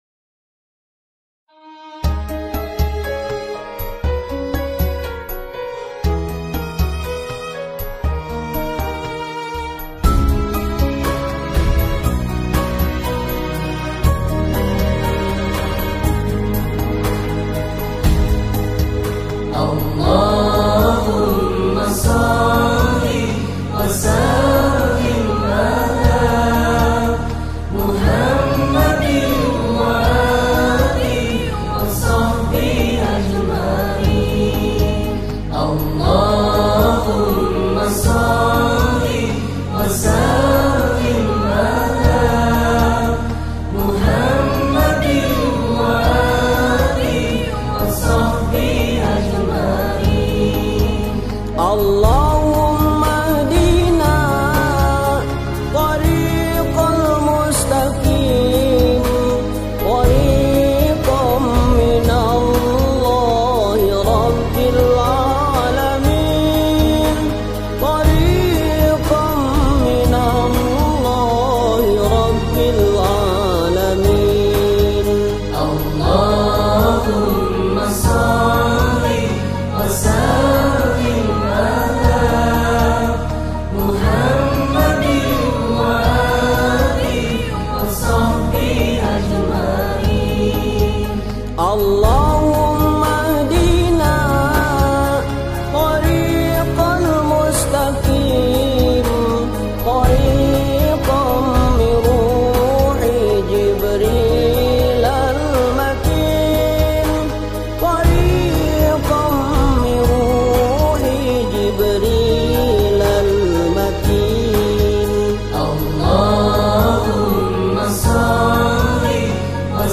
Saya menulis puisi Senandung Ramadan sambil mendengarkan lantunan Sholawat Thoriqiyyah dari Thoriqot Qodiriyah Naqsabandiyyah (TQN) yang amat sangat menyentuh hati.
(Sholawat Thoriqoh Qodiriyyah Naqsabandiyyah — Suryalaya)